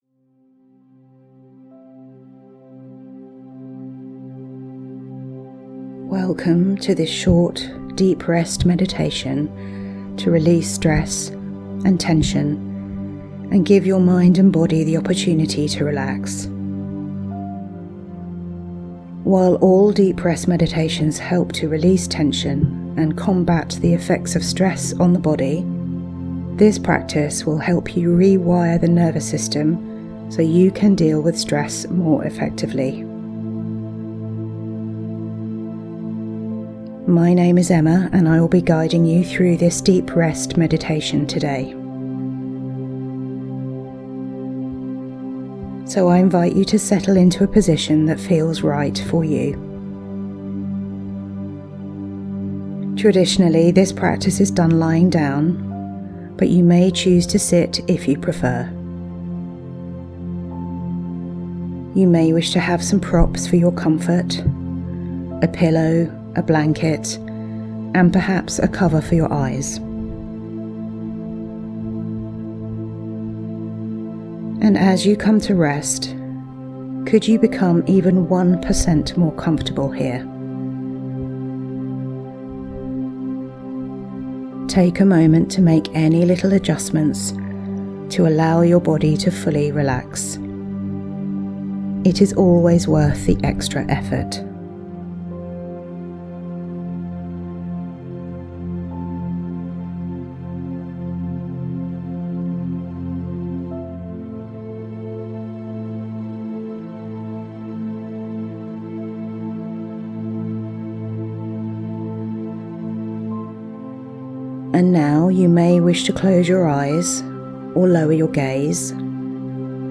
deep-rest-meditation-to-relieve-stress-with-background-music.mp3